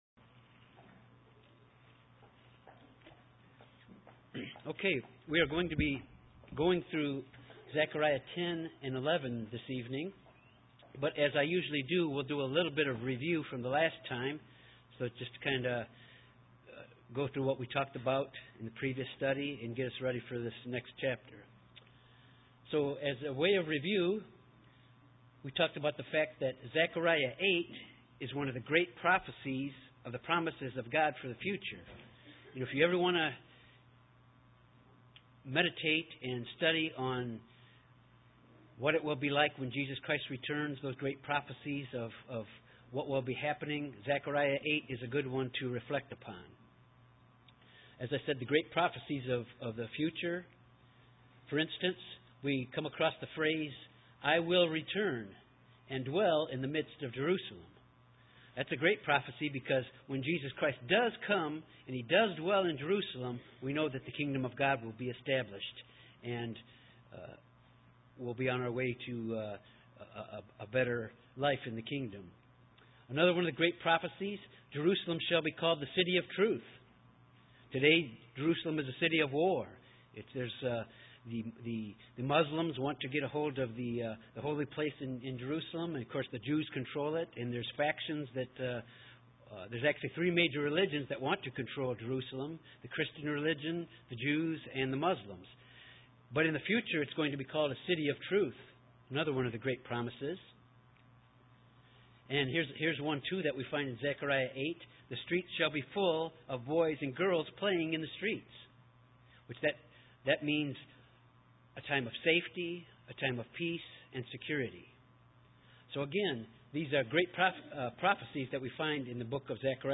Bible study on Zechariah 10-11 with handout: Zechariah 10-11. Chapters 9 and 10 speak of the deliverance and restoration that the Messiah will bring. Chapter 11 prophecies the nation rejecting the Messiah and the consequences brought about by that.